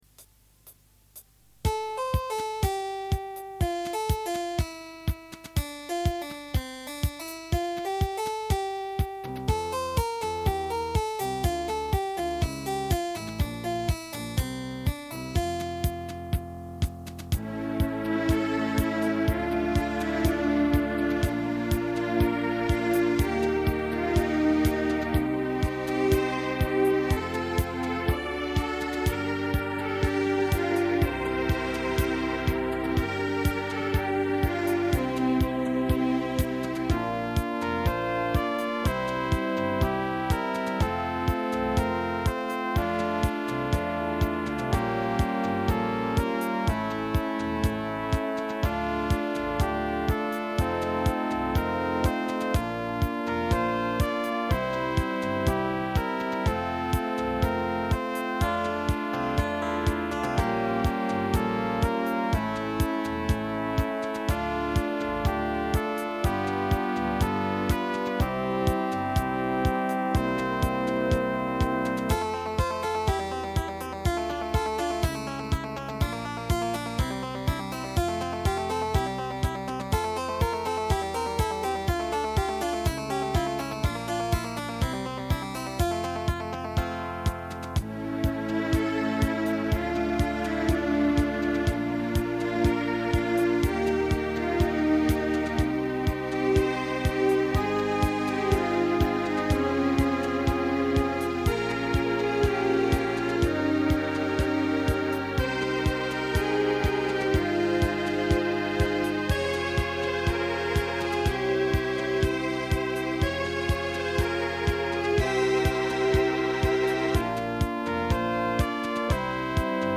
(tango-like style)